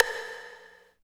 59 VERB STIK.wav